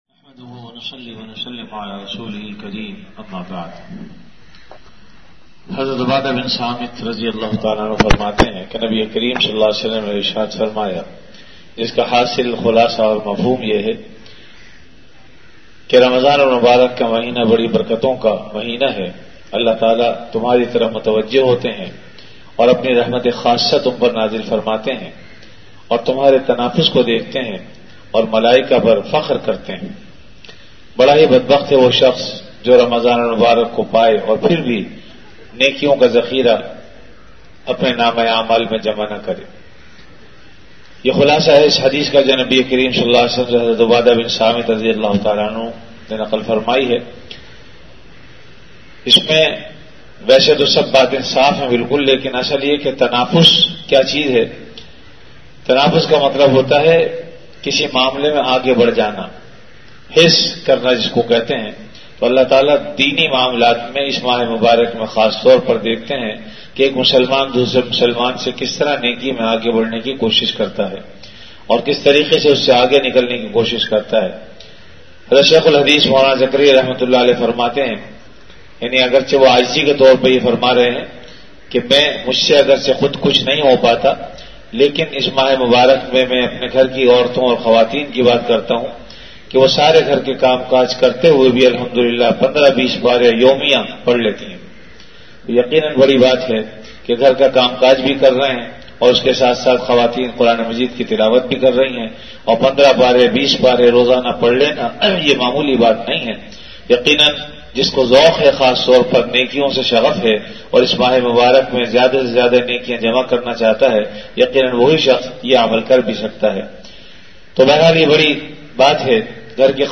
Ramadan - Dars-e-Hadees · Jamia Masjid Bait-ul-Mukkaram, Karachi
VenueJamia Masjid Bait-ul-Mukkaram, Karachi
Event / TimeAfter Fajr Prayer